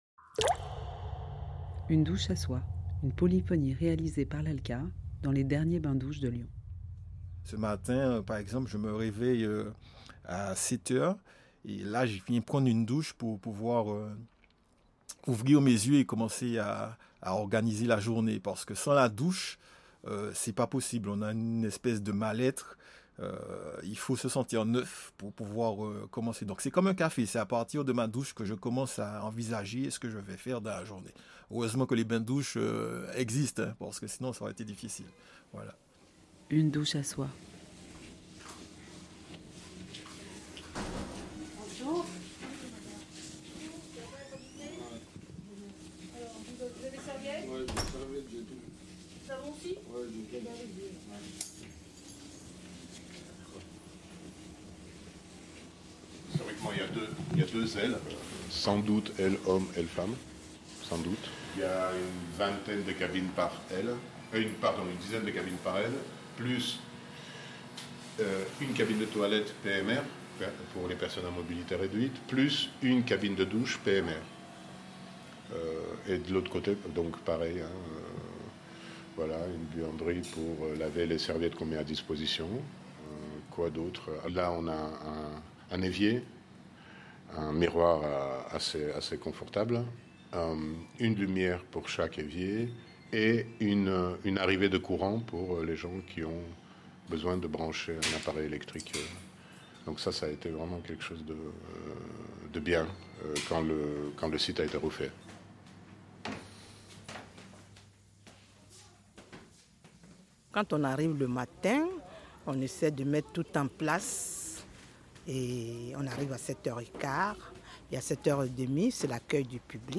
La polyphonie "Une douche à soi" questionne les frontières de l'intime, les pratiques de soin du corps et les normes de propreté à l'intérieur des bains-douches de Lyon.